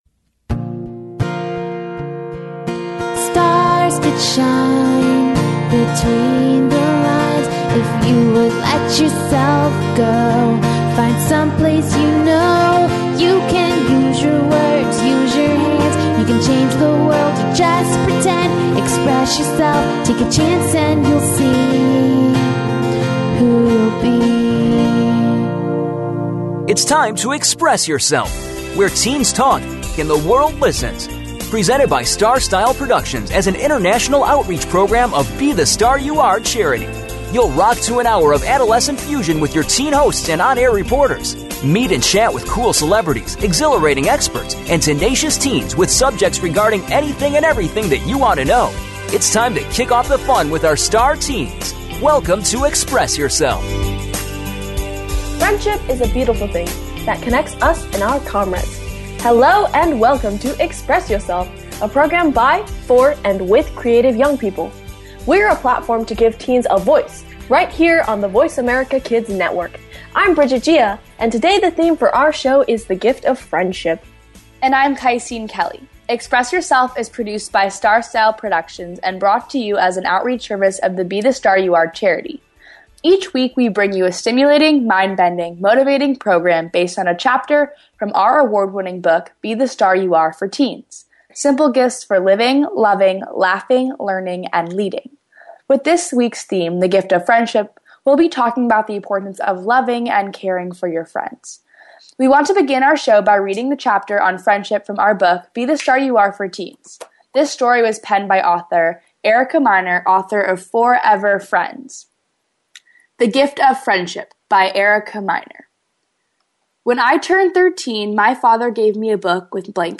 The ladies then interview author